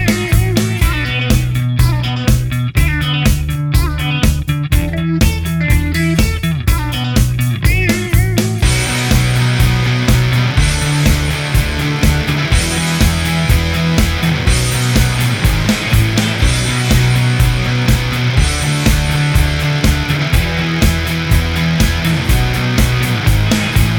No Saxophone Pop (2000s) 3:37 Buy £1.50